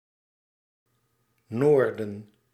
Ääntäminen
France: IPA: [lə nɔʁ]